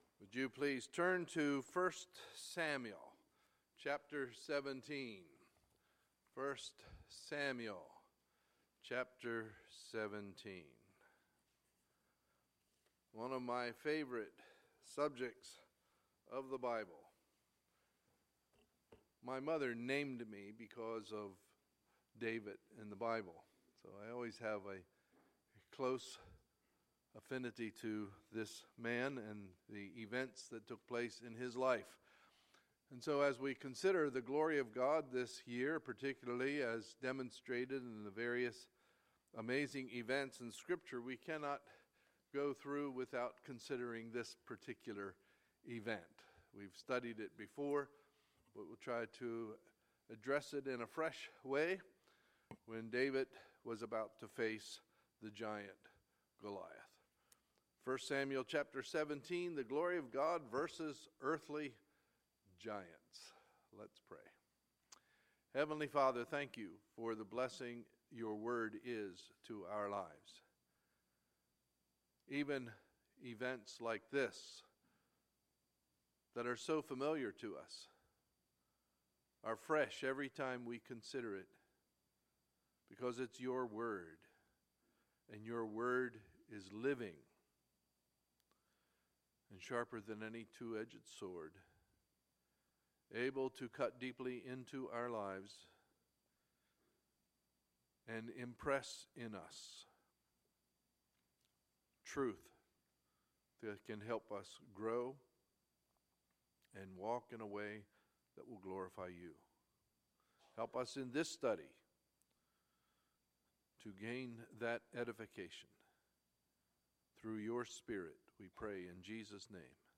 Sunday, September 11, 2016 – Sunday Morning Service